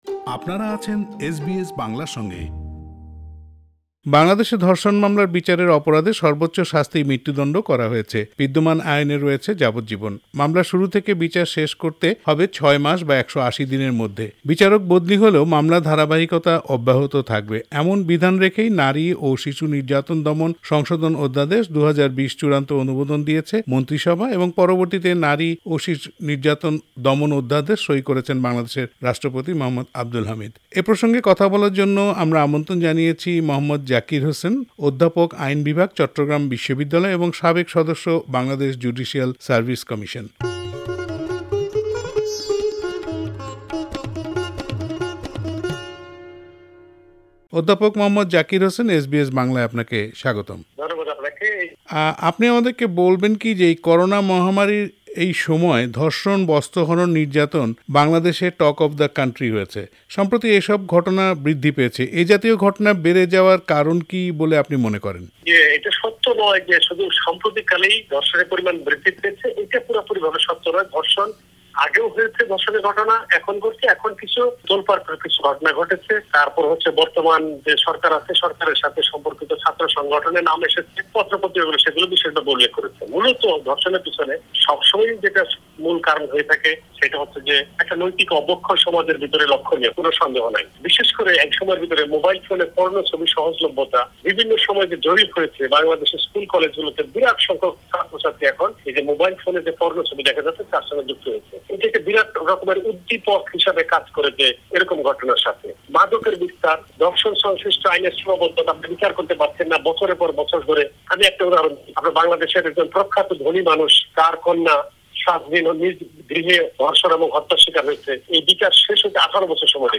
এ প্রসঙ্গে এসবিএস বাংলার সাথে কথা বলেছেন